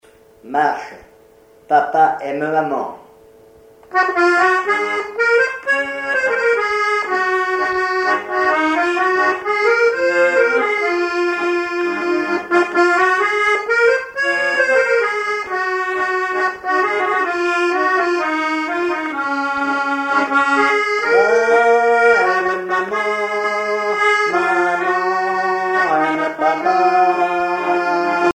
accordéon(s), accordéoniste
danse : marche
Pièce musicale inédite